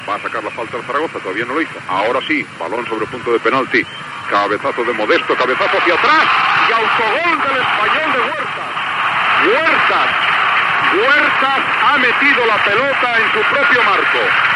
Narració de l'autogol de l'Espanyol.
Esportiu